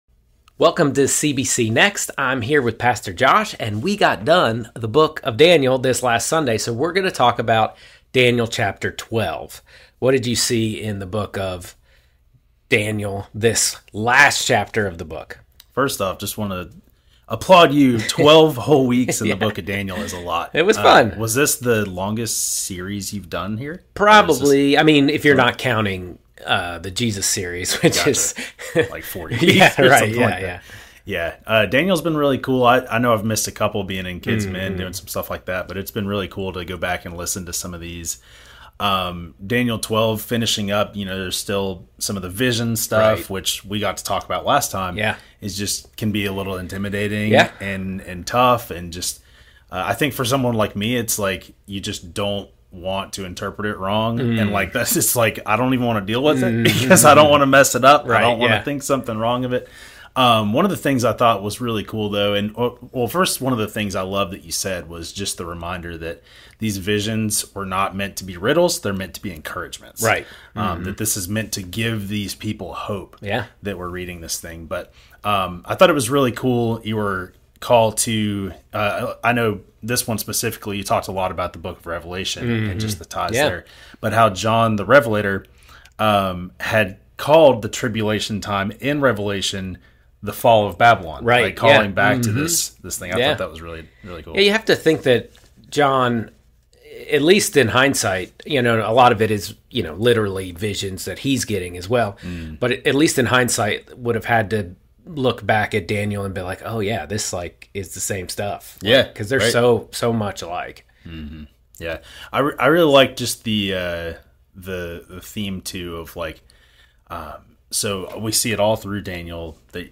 As a way to dive deeper in this theologically rich book, we will be posting a follow up conversation on Facebook and YouTube every Tuesday at 6 with some guests that will bring a different perspective on Daniel.